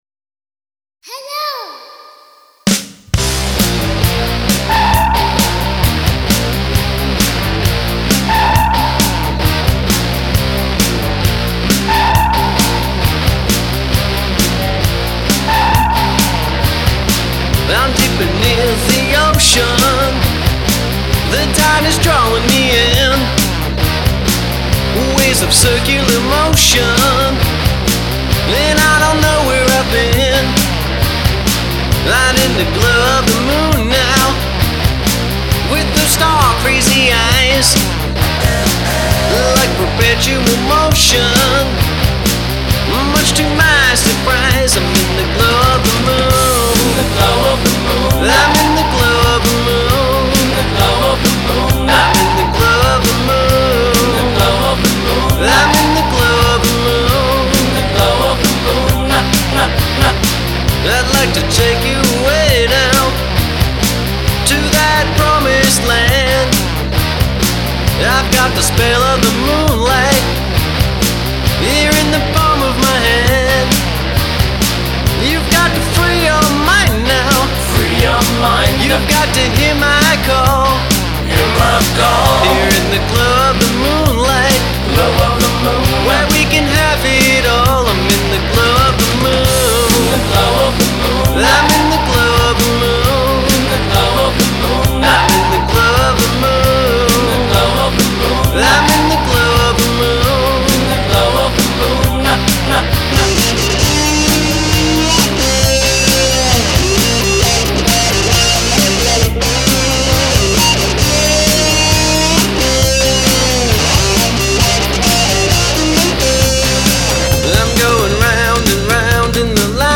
Rock & Roll
Prog rock